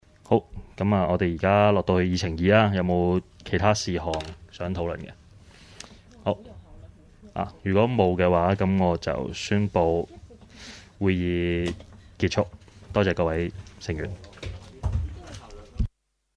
工作小组会议的录音记录
地点: 元朗桥乐坊2号元朗政府合署十三楼会议厅